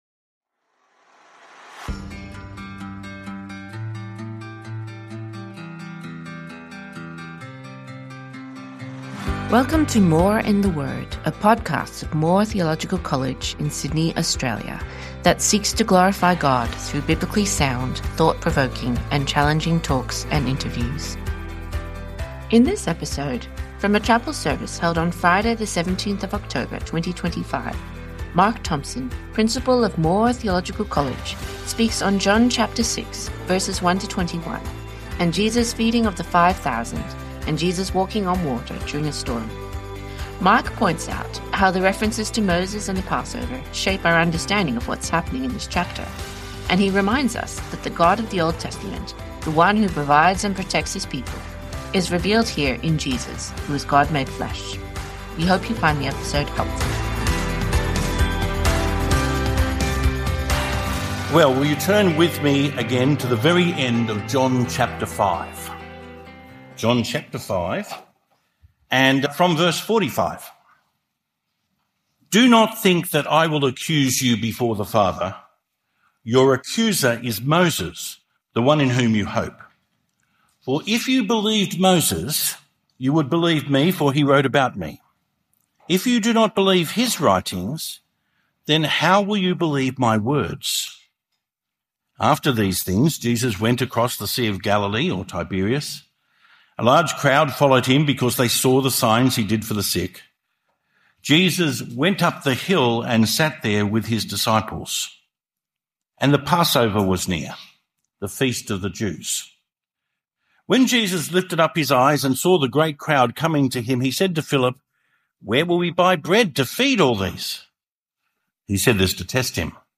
In this episode, from a chapel service held on Tuesday, 14 October 2025